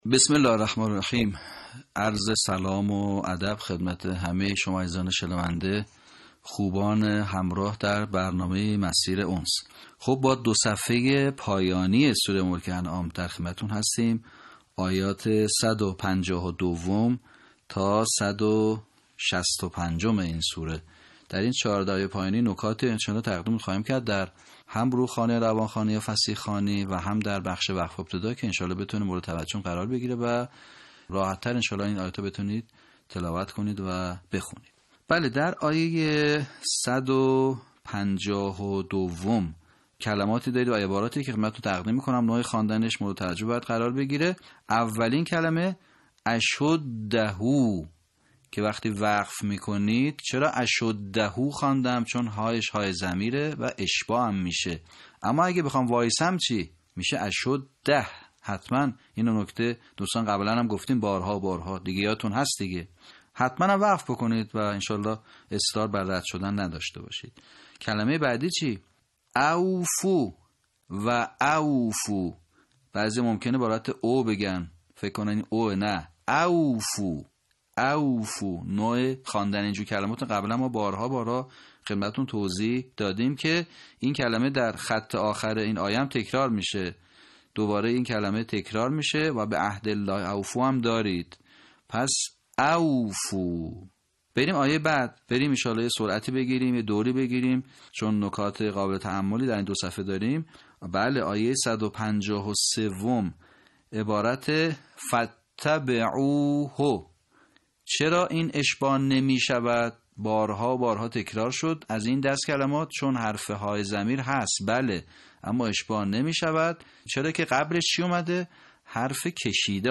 صوت | آموزش صحیح‌خوانی آیات ۱۵۲ تا ۱۶۵ سوره انعام